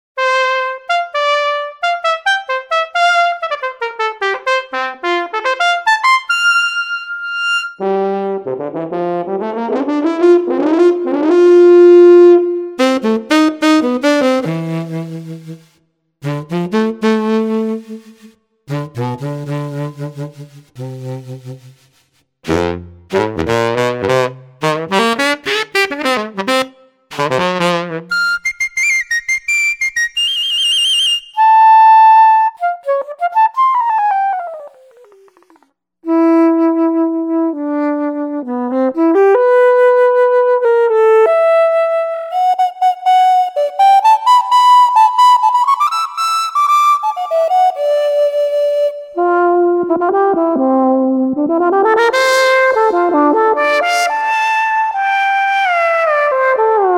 VL70-m Volume 2 Audio Demo (1.8 megs) Click on the speaker to download a high quality MP3 audio demo of this soundbank. This demo contains 19 segments recorded direct from the VL70-m played with a MIDI wind controller. The sounds you hear in the demo are as follows: 01 *ClassTpt, 04 *Horn-, 06 *JazzTn2, 07 *BariSax, 09 *Piccolo, 11 *JzFlute, 13 *Flutofn, 17 *Breezer, 25 *Beat It, 27 *Matrix!, 37 *[] Sqr., 40 *Moog Ld, 46 *SteelDr, 48 *PassGtr, 50 *ChorsGtr, 52 *DistGtr, 55 *WahBass, 59 *BorgHrn, and 60 *Brekord.